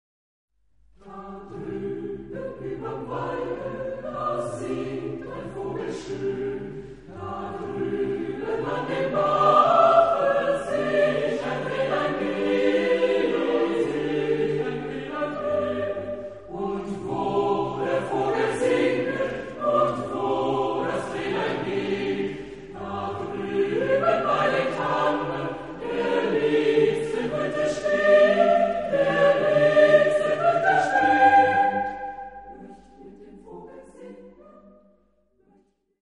Epoque: 19th century
Genre-Style-Form: Secular ; Romantic
Type of Choir: SATB  (4 mixed voices )
Tonality: A minor